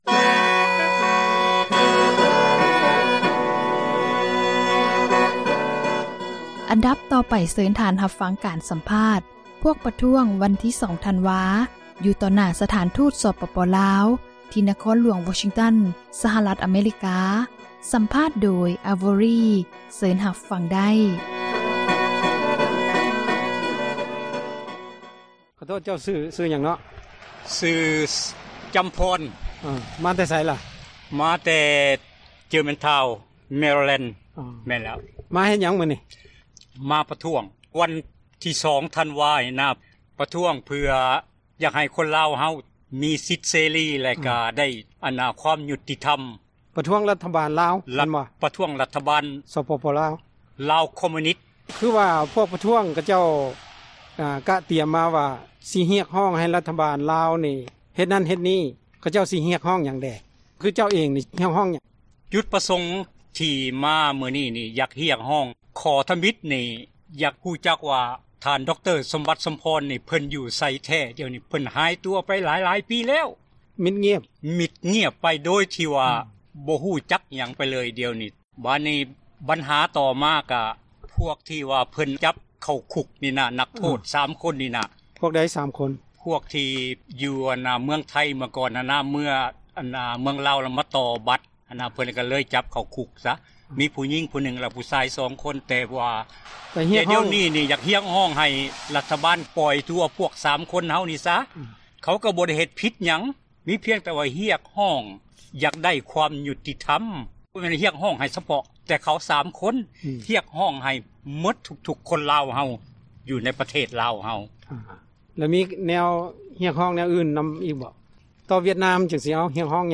ສໍາພາດ 11 ອົງການ ປະທ້ວງ ວັນທີ 2 ທັນວາ
ຊາວລາວ ຢູ່ຕ່າງປະເທດ ຈາກ 11 ອົງການ ໄດ້ພາກັນໂຮມຊຸມນຸມ ຢູ່ຕໍ່ໜ້າສະຖານທູດ ສປປລາວ ໃນວັນທີ 1 ທັນວາ 2018 ທີ່ນະຄອນຫຼວງ ວໍຊິງຕັນ ດີຊີ ສະຫະຣັຖ ອະເມຣິກາ